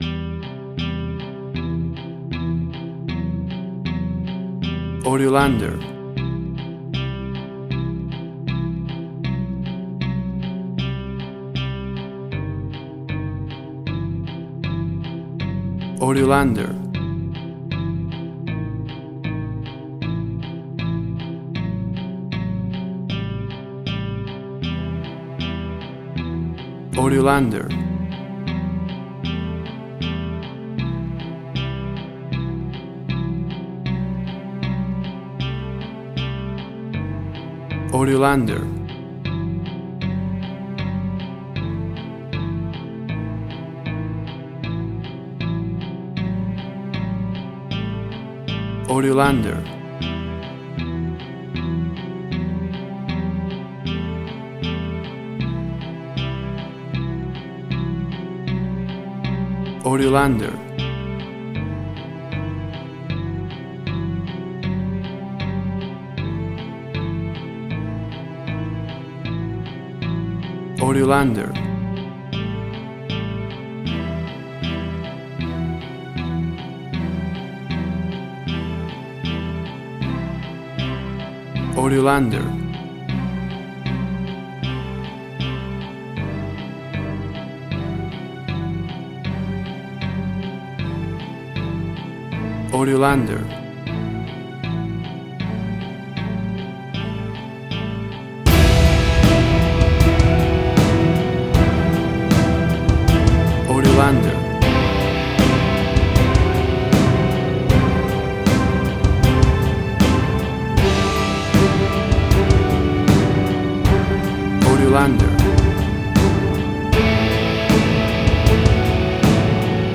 A Horror Sound Alike track
Tempo (BPM): 78